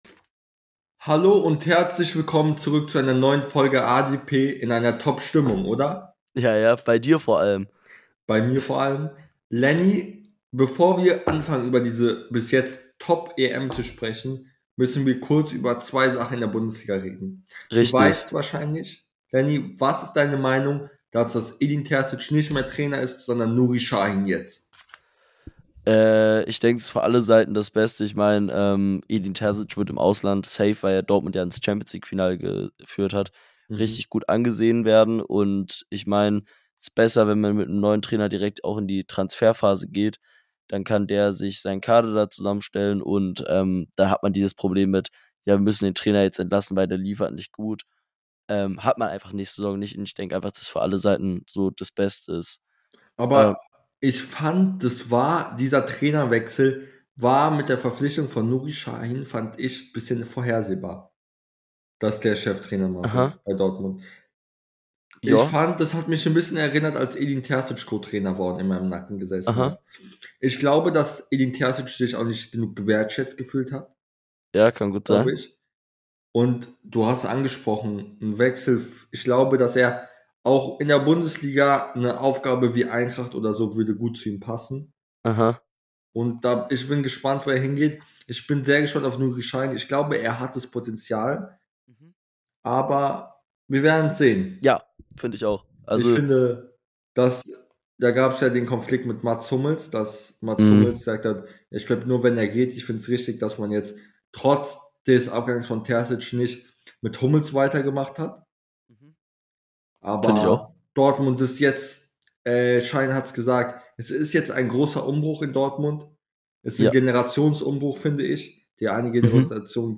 In der heutigen Folge reden die beiden Hosts über den Transfersommer , blicken auf den ersten Spieltag der EM und tippen den 2 Spieltag